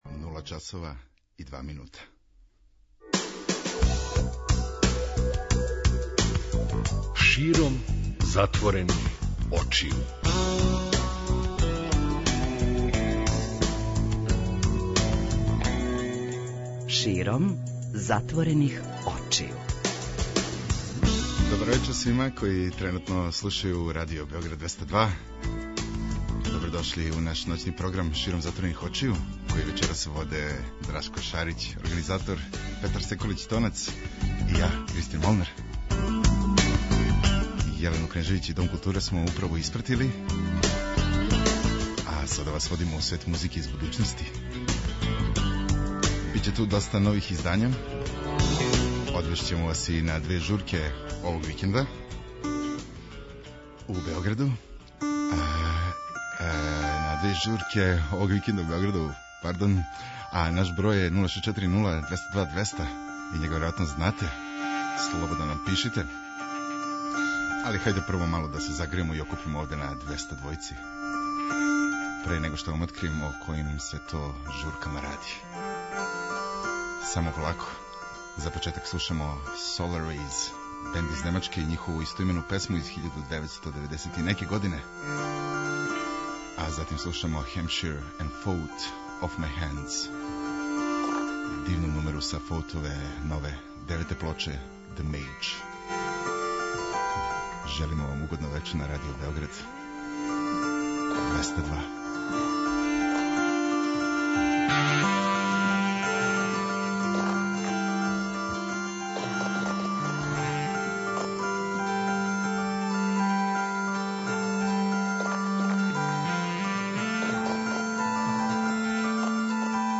Ди-џеј
преузми : 54.99 MB Широм затворених очију Autor: Београд 202 Ноћни програм Београда 202 [ детаљније ] Све епизоде серијала Београд 202 Тешке боје Пролеће, КОИКОИ и Хангар Устанак Устанак Устанак